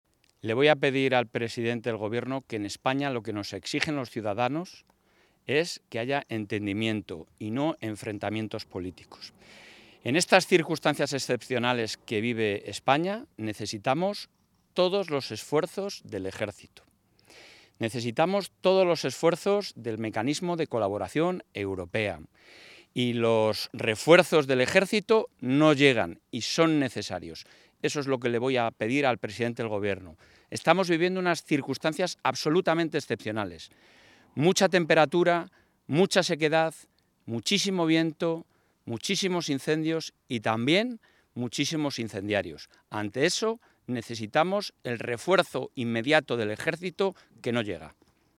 Declaraciones del presidente de la Junta.